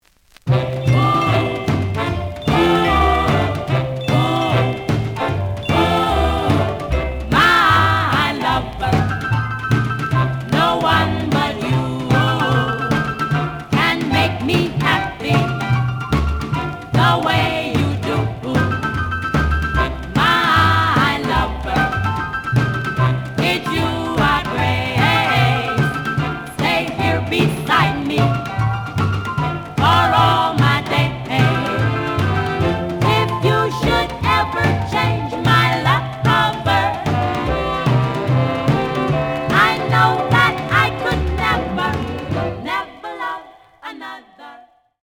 The audio sample is recorded from the actual item.
●Format: 7 inch
●Genre: Rock / Pop
Slight edge warp.